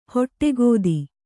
♪ hoṭṭegōdi